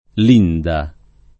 vai all'elenco alfabetico delle voci ingrandisci il carattere 100% rimpicciolisci il carattere stampa invia tramite posta elettronica codividi su Facebook Linda [ l & nda ; ingl. l & ndë ] pers. f. — non com., in it., il masch.